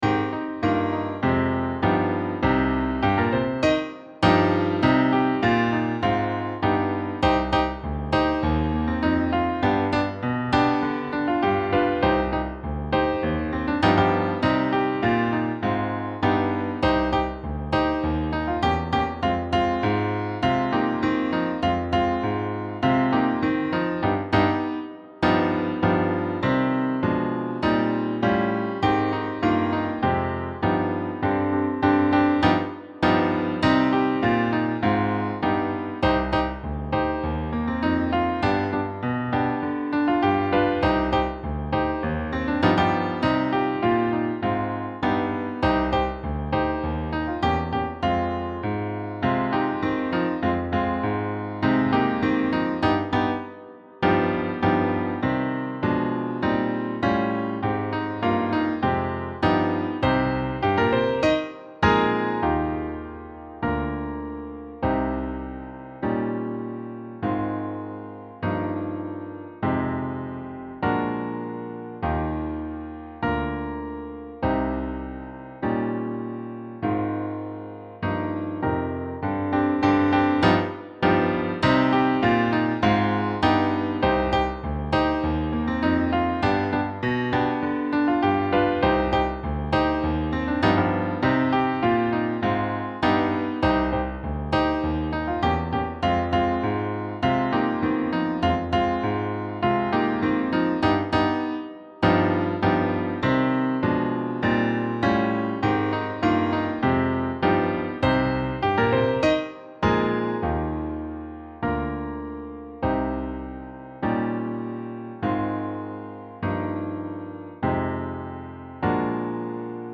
Treble Choir High
Ain't+Gonna+Let+Nobody+Turn+Me+Around+-+Piano.mp3